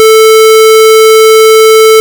Als Schallschwingung klingt eine Rechteckschwingung so
Square-440Hz.ogg